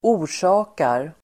Uttal: [²'o:r_sa:kar]